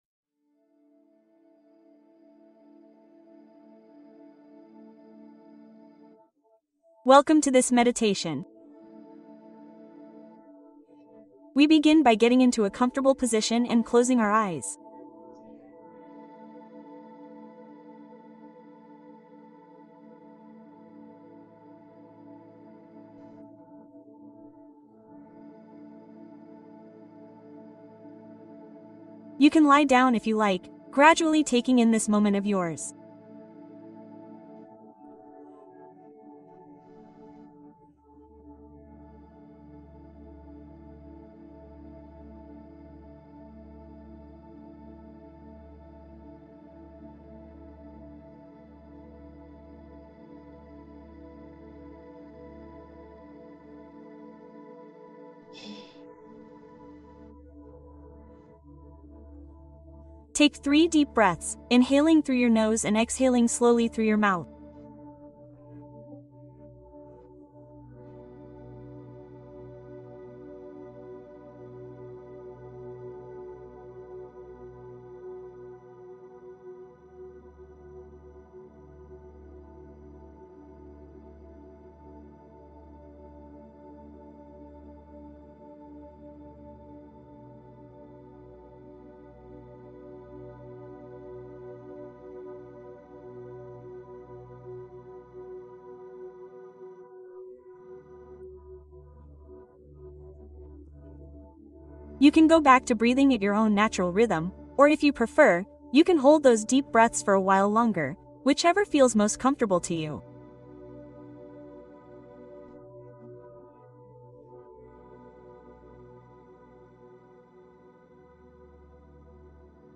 Sanar el corazón: una meditación para soltar cargas emocionales